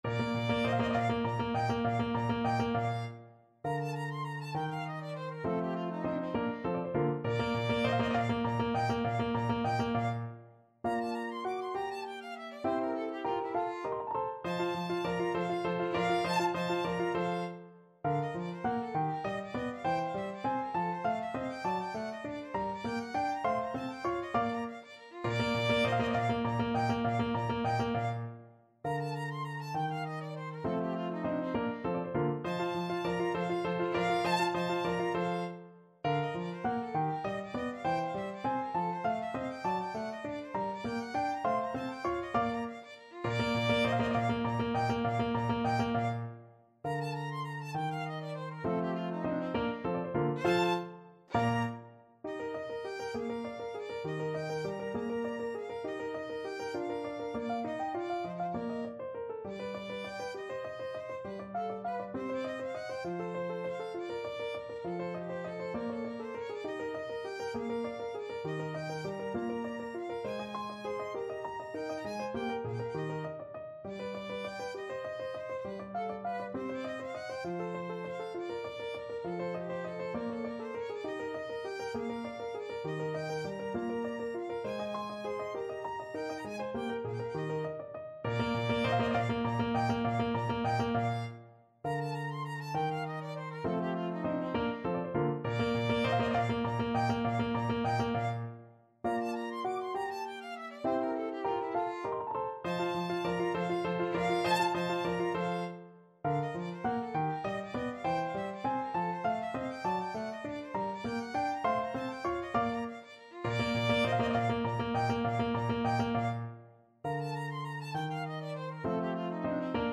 3/4 (View more 3/4 Music)
Allegro vivace =200 (View more music marked Allegro)
Classical (View more Classical Violin Music)